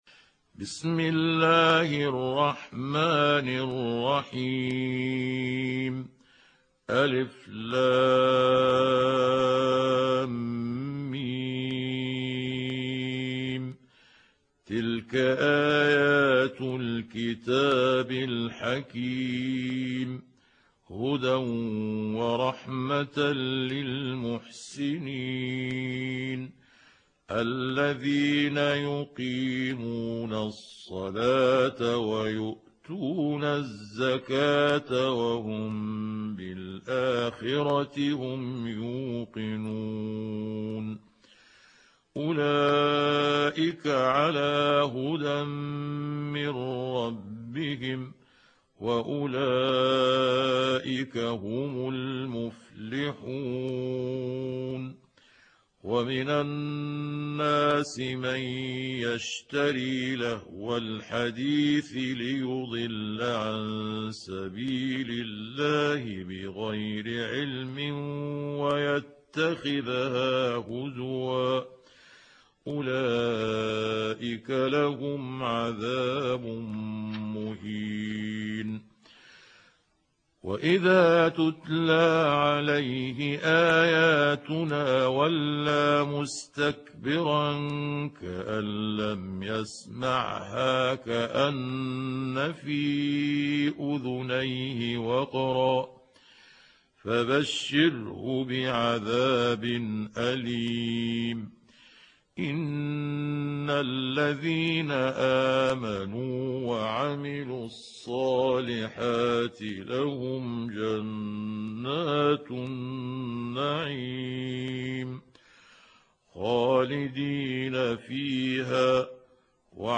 دانلود سوره لقمان mp3 محمود عبد الحكم روایت حفص از عاصم, قرآن را دانلود کنید و گوش کن mp3 ، لینک مستقیم کامل